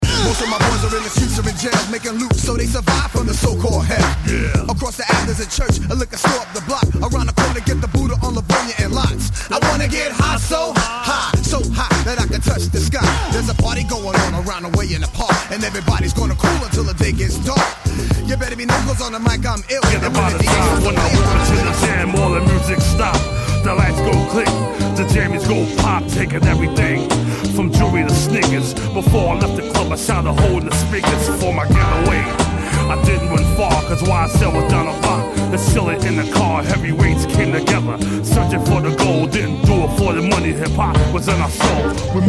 gangsta rap/g-funk